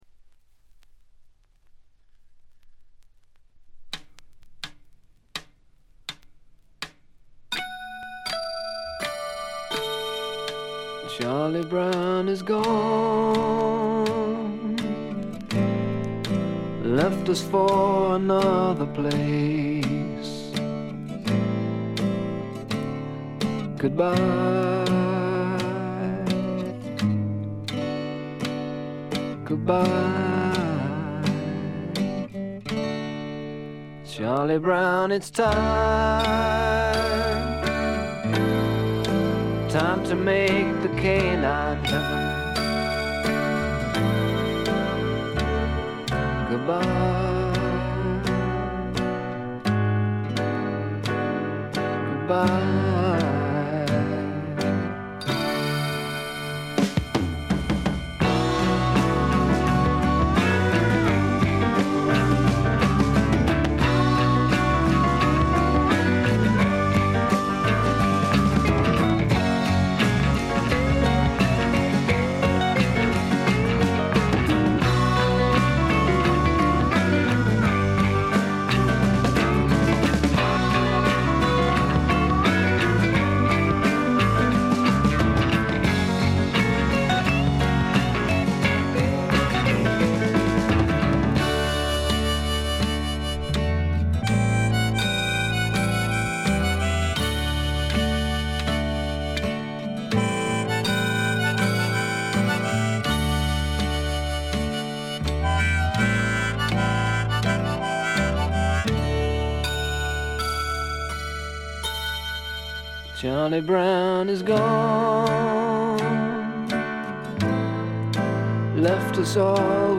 部分試聴ですが、ほとんどノイズ感無し。
試聴曲は現品からの取り込み音源です。